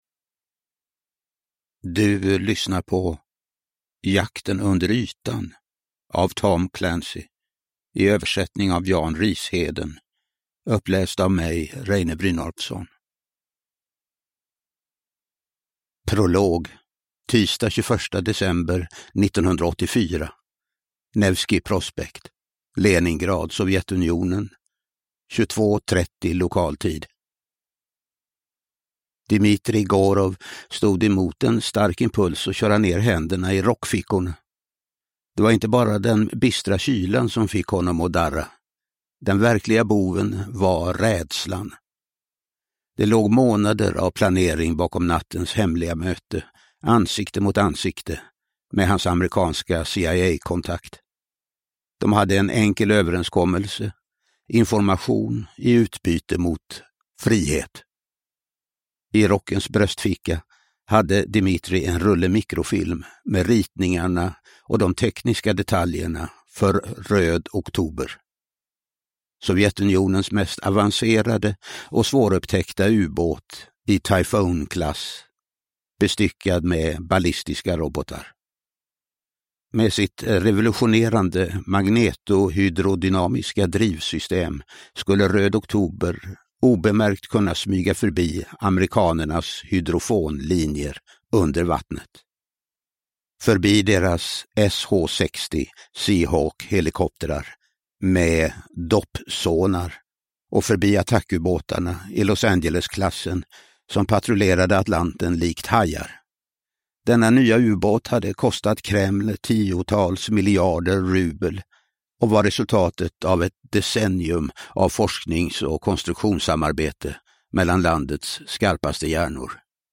Jakten under ytan – Ljudbok
Uppläsare: Reine Brynolfsson